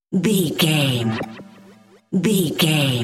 Sound Effects
Atonal
magical
mystical